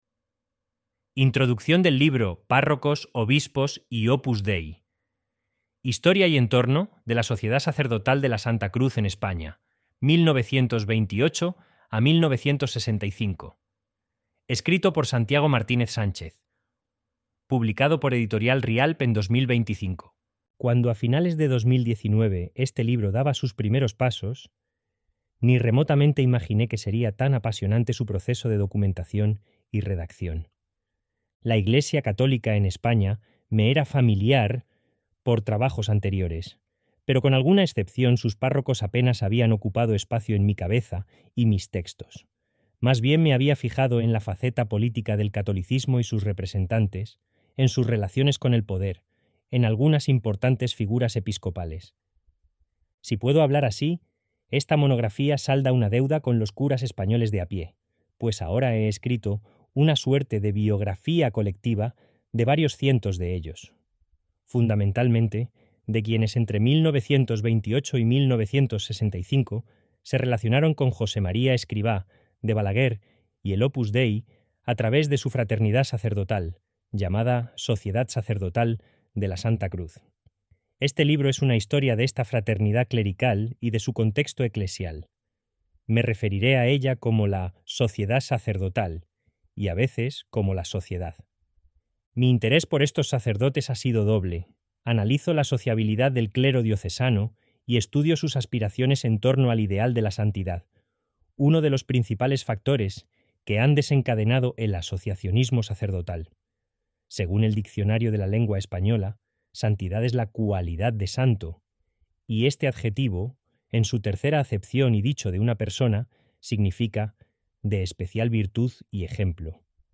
Table of contents and introduction Audio book: introduction